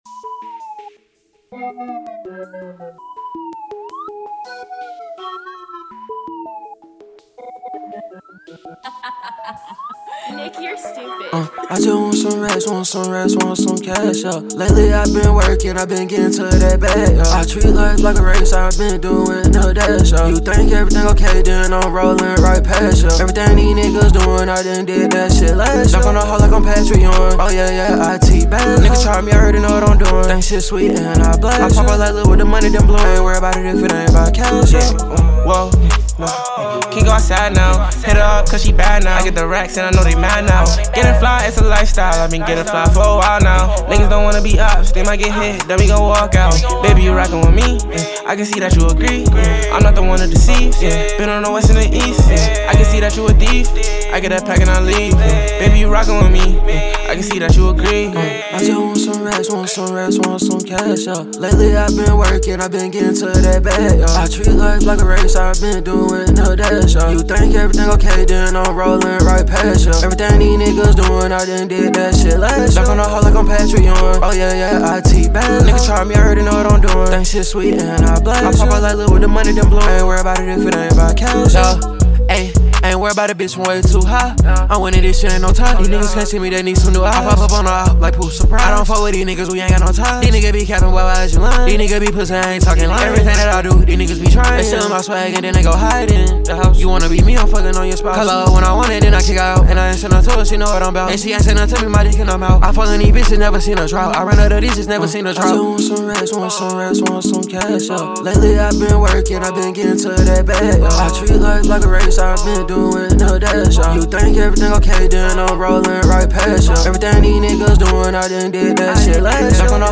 это энергичная хип-хоп трек